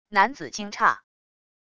男子惊诧wav音频